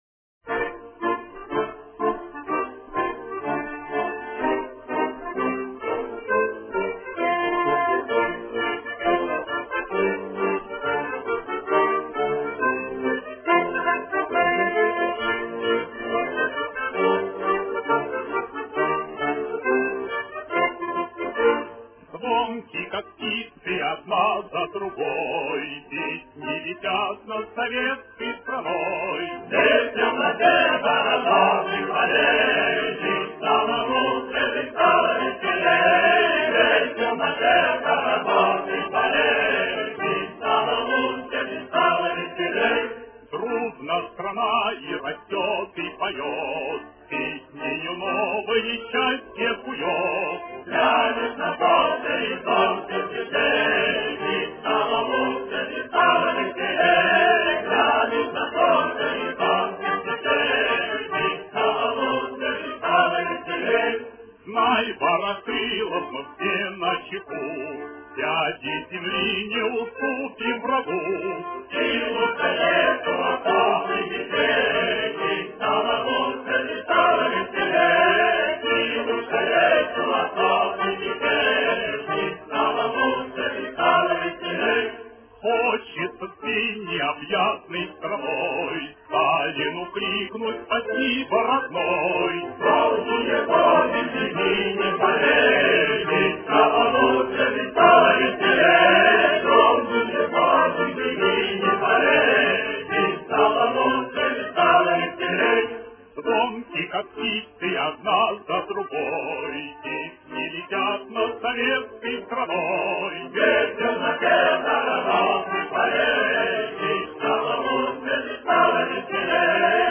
Заканчивалось пение повторением первого куплета.